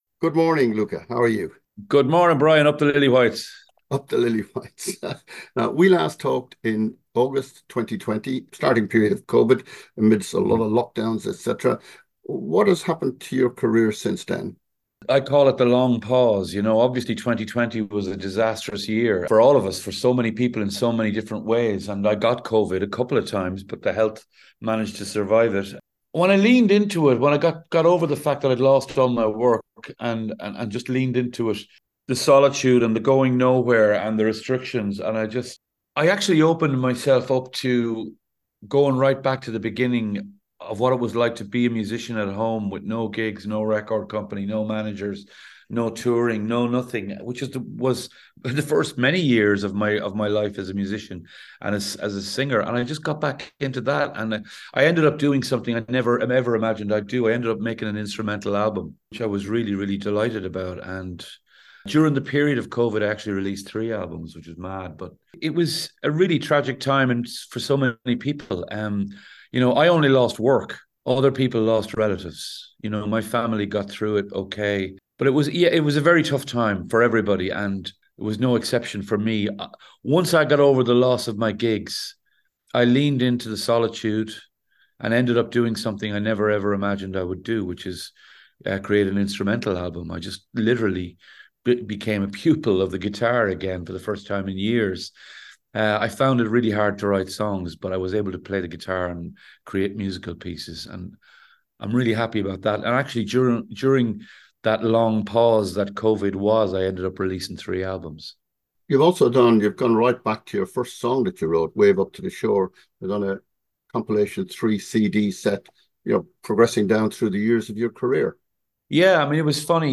Interview 2024